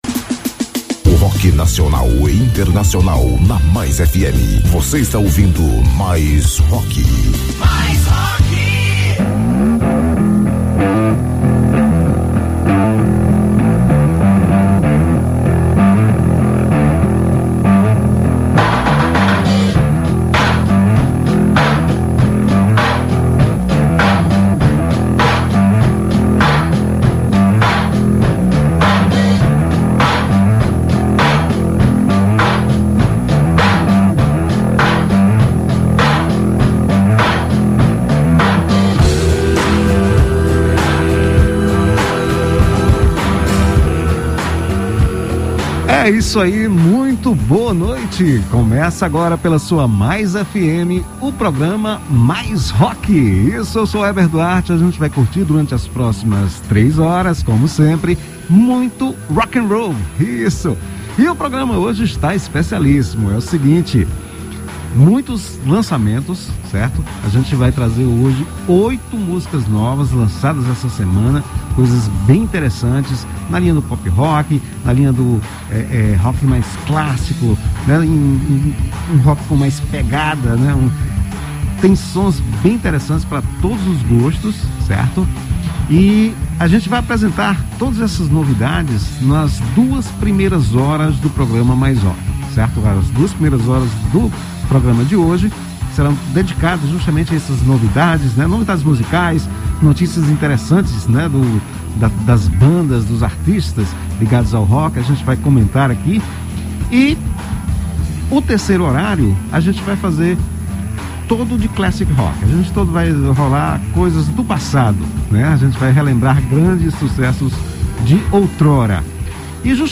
PODCAST MAIS ROCK – O melhor e mais completo programa de ROCK do Ceará